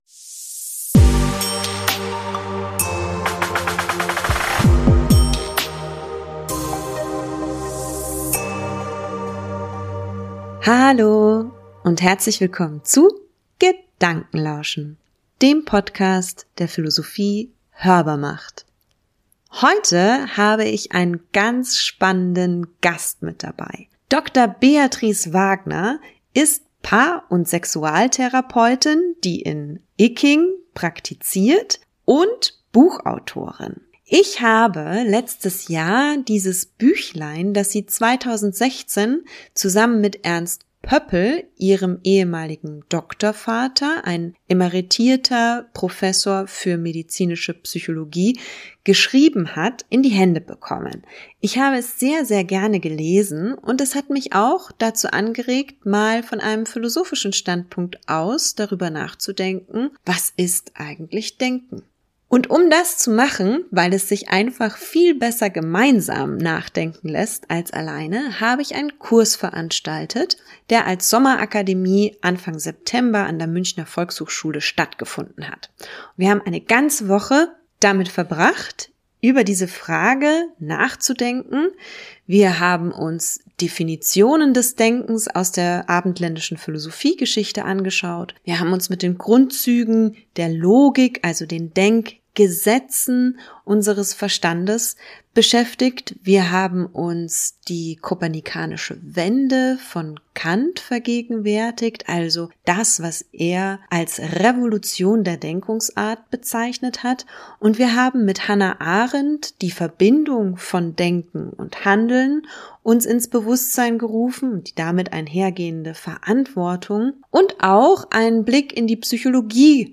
Leider gab es ein paar Übersteuerungen bei der Aufzeichnung über Zoom, insbesondere bei meinen Redeanteilen, die ich nicht beheben konnte.